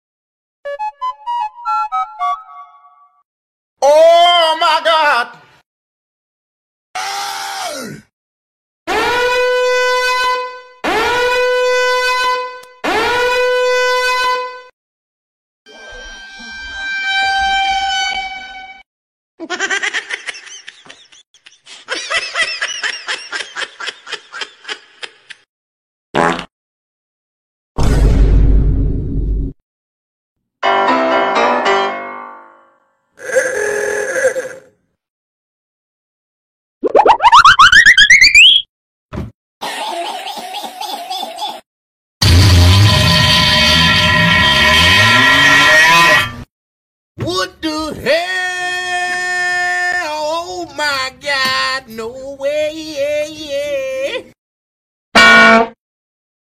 All Goofy Ahh Sound Effects.mp3